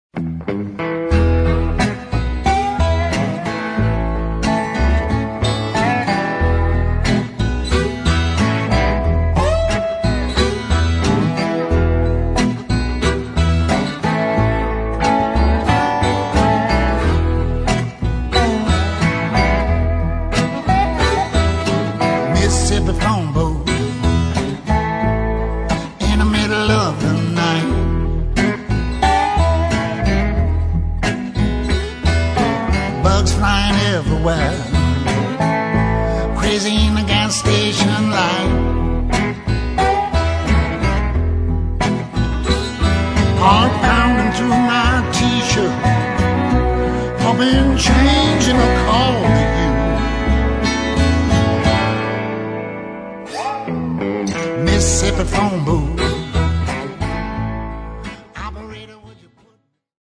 Dobro, Lap Steel, and Background Vocals
Bass, Tic-Tac Bass and String Arrangements
Acoustic and Electric Guitars
Violin and String Arrangements